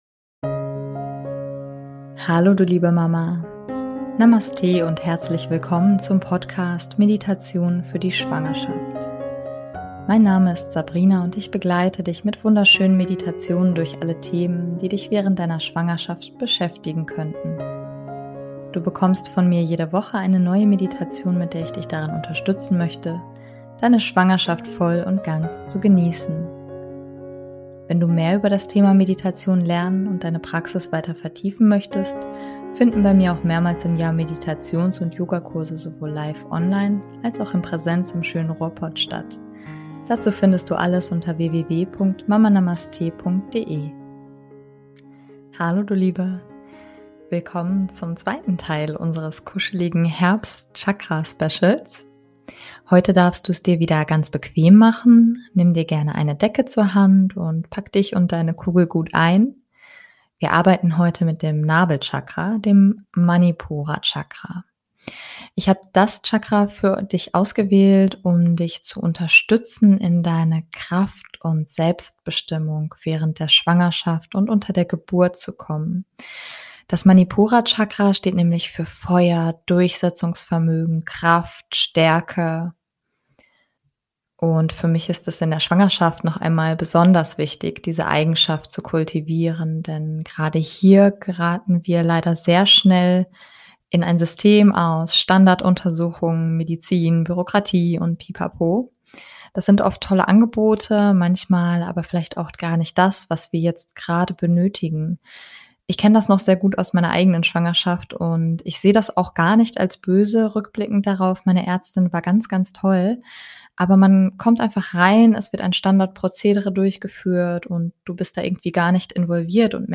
#056 - Meditation - Nabel Chakra - Selbstbestimmung für Schwangere ~ Meditationen für die Schwangerschaft und Geburt - mama.namaste Podcast